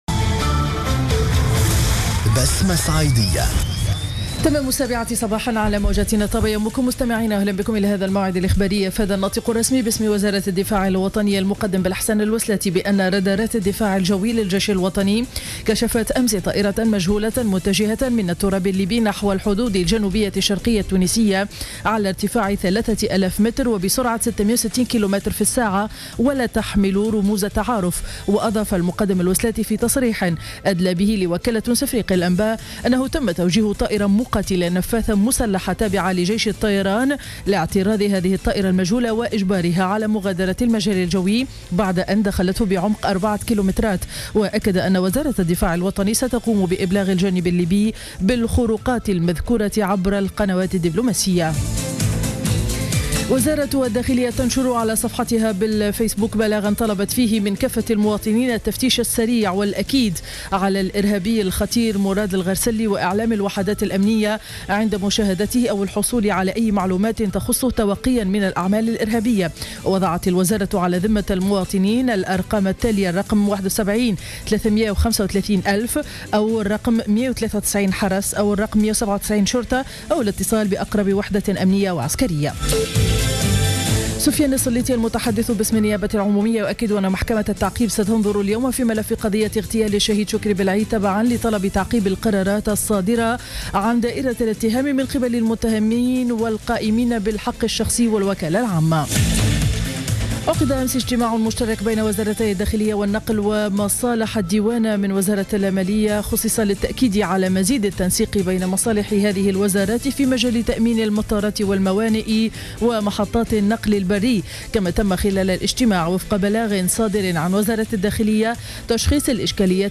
نشرة أخبار السابعة صباحا ليوم الخميس 2 أفريل 2015